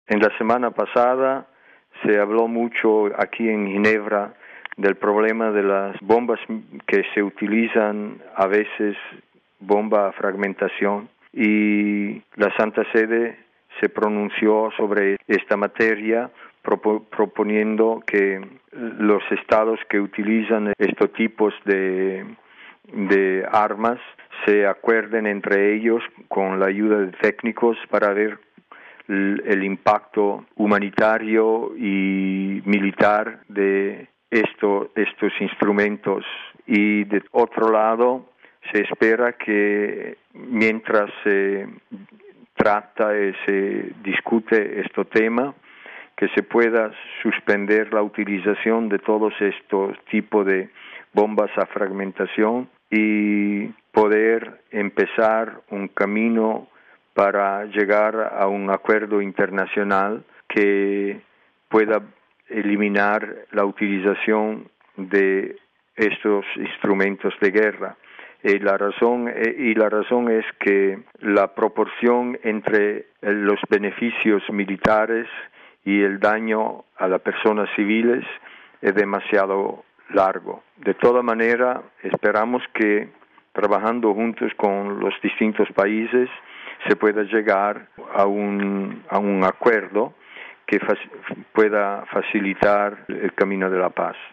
Declaraciones de Monseñor Silvano Maria Tomasi sobre la prohibición o limitación del uso de armas